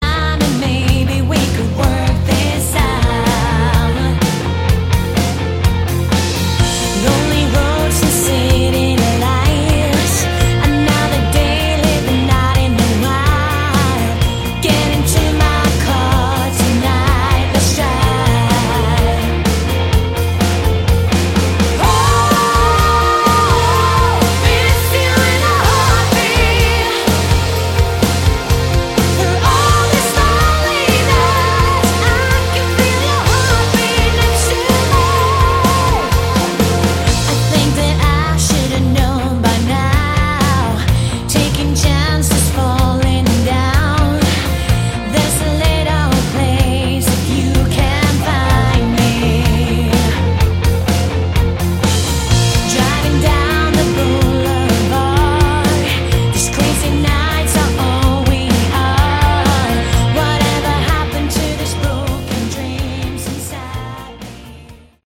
Category: Melodic Rock
lead and backing vocals
keyboards
bass, guitars
drums
Nice smooth yet punchy production too.